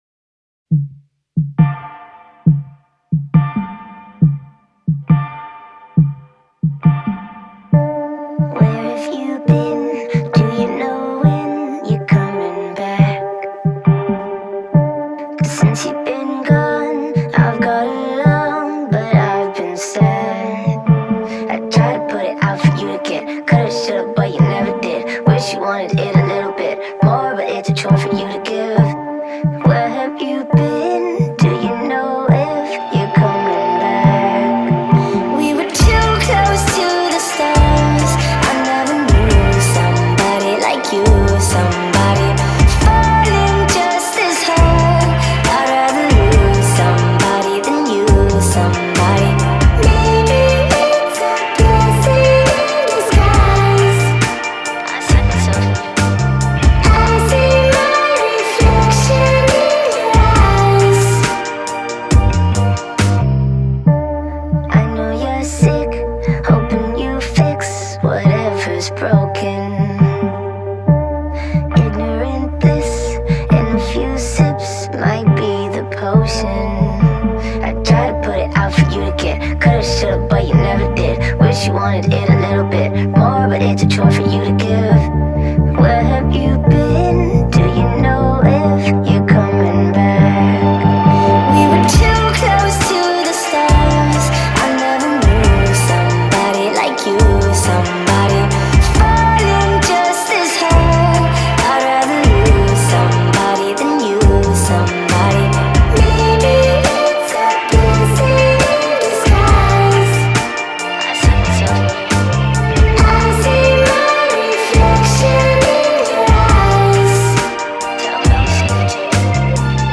Зарубежная музыка